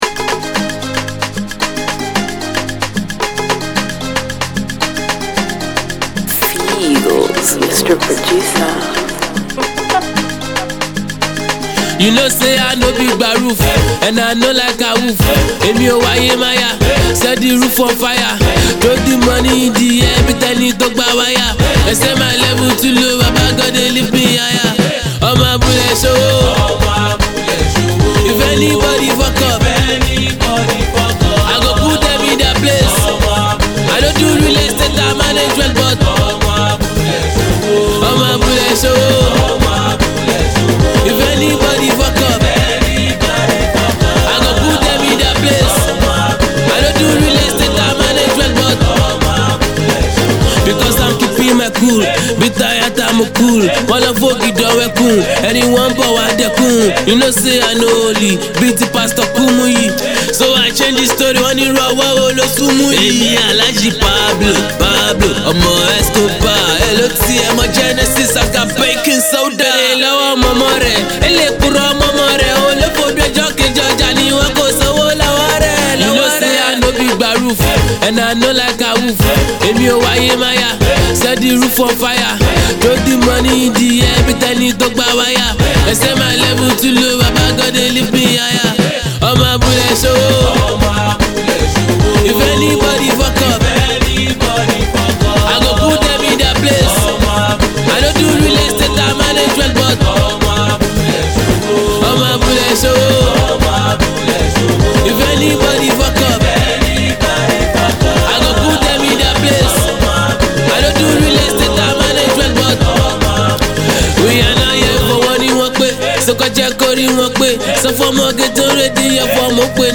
Delivered in his usual street style